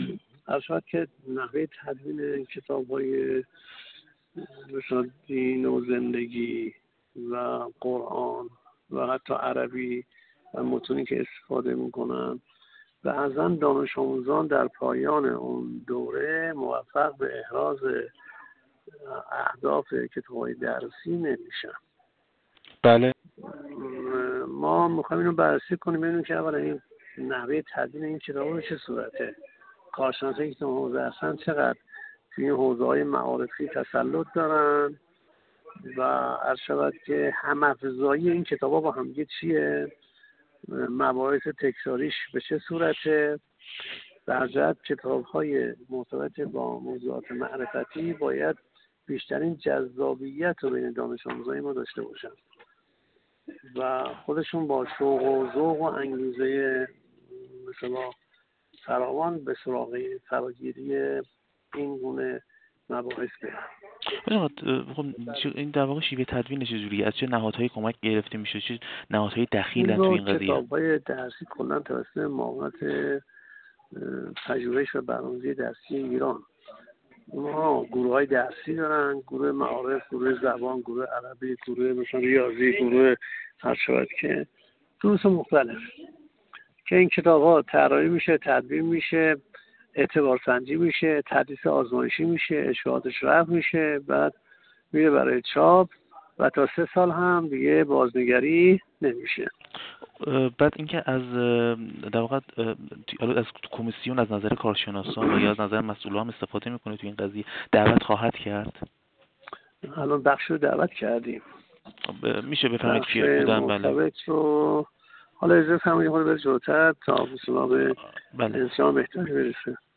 یزدی‌خواه در گفت‌وگو با ایکنا بیان کرد: